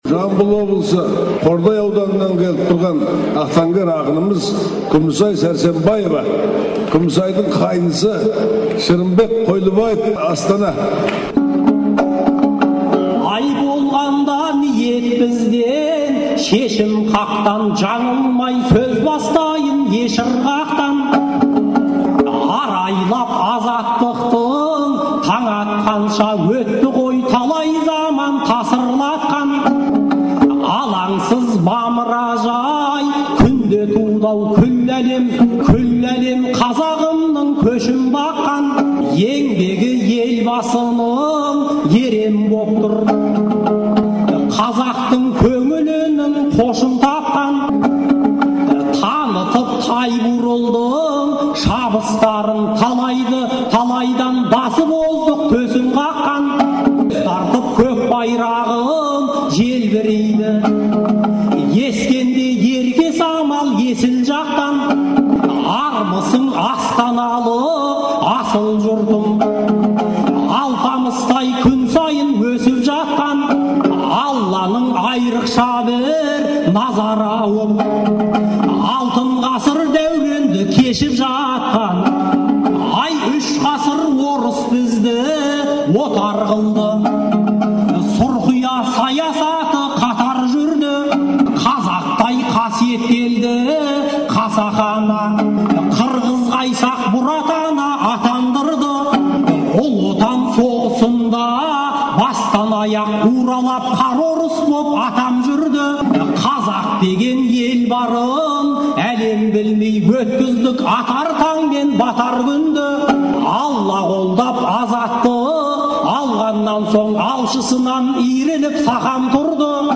2010 жылы желтоқсанда «Тәуелсіздік тағылымы» деген тақырыпта Астанада өткен айтыстың екінші күнгі соңғы жұбы